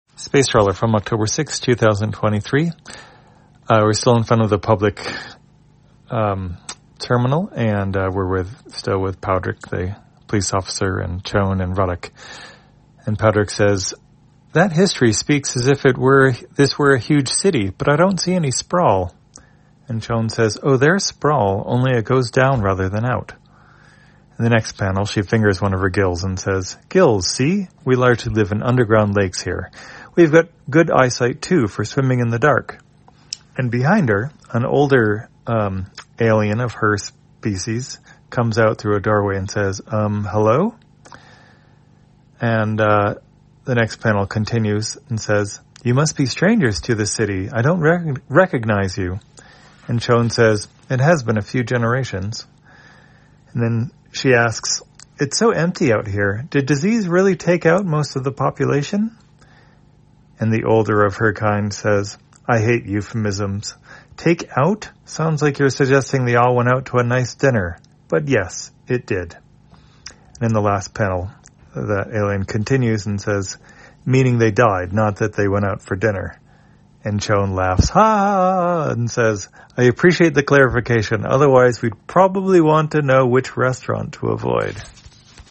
Spacetrawler, audio version For the blind or visually impaired, October 6, 2023.